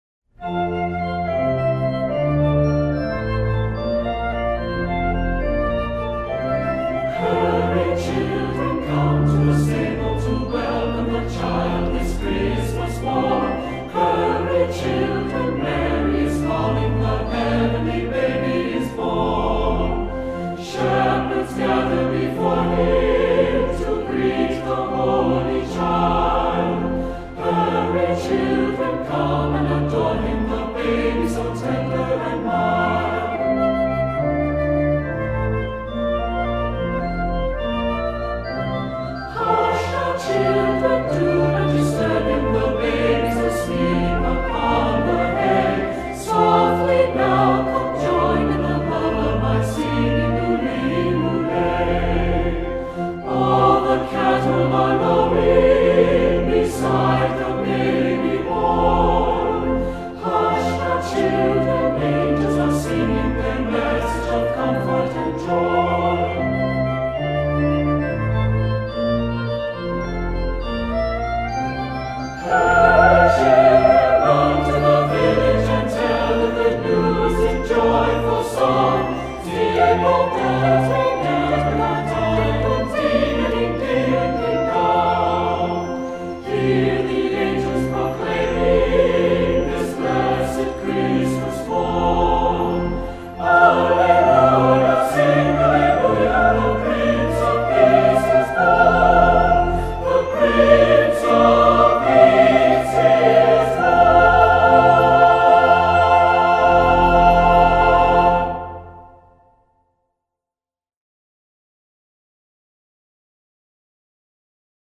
Voicing: SAB and Piano